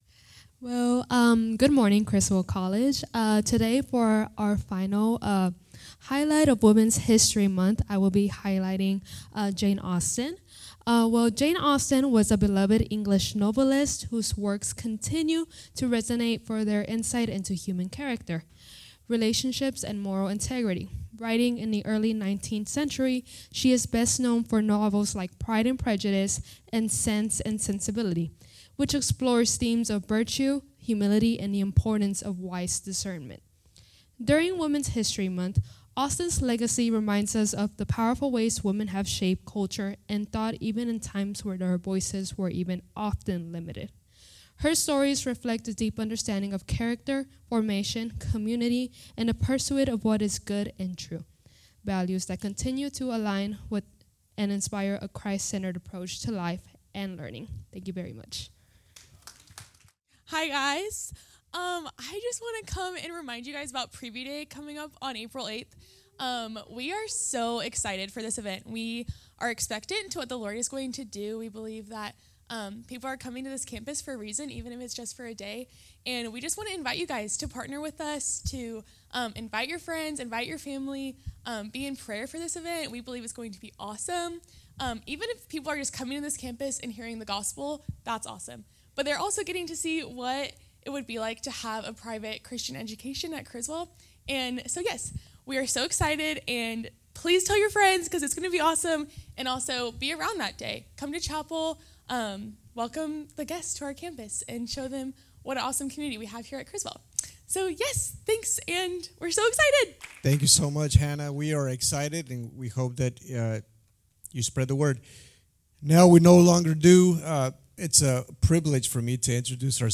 Criswell College Chapel Service.